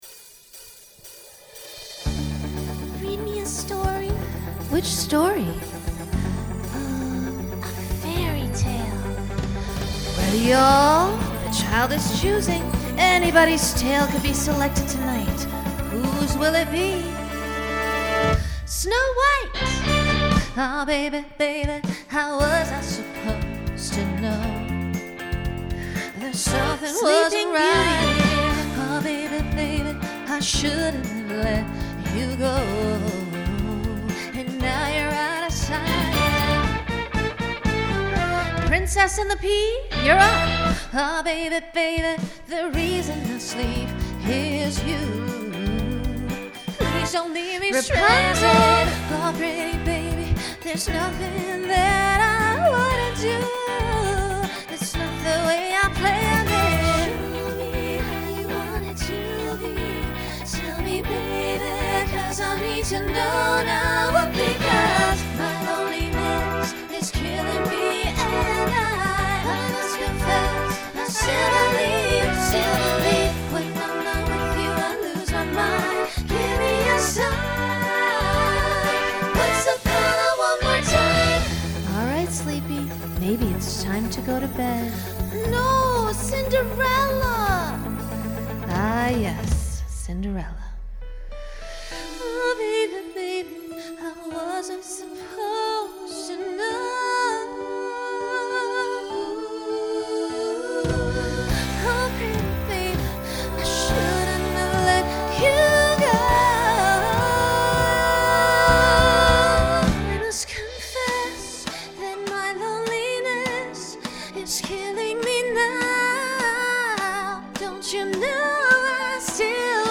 Instrumental combo
Broadway/Film , Pop/Dance
Voicing SSA